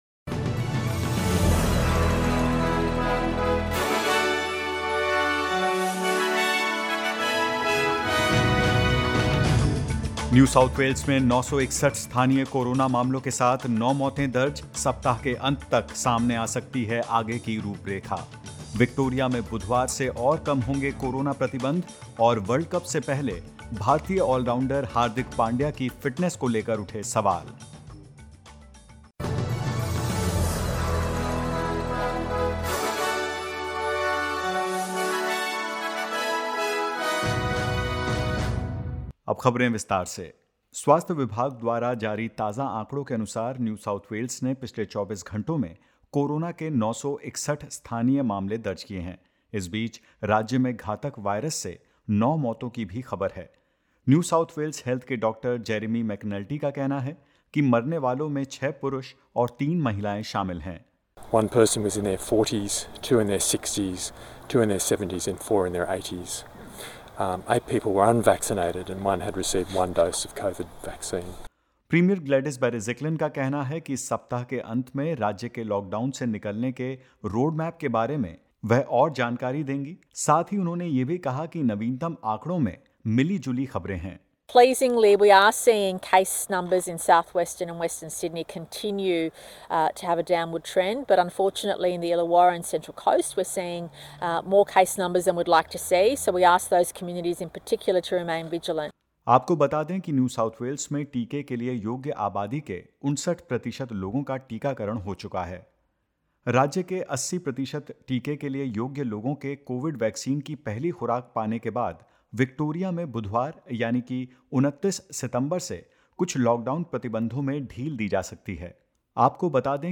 In this latest SBS Hindi News bulletin of Australia and India: 59.25 percent of the eligible population in NSW gets fully vaccinated; With two deaths, Victoria records 779 new locally acquired COVID-19 cases and more.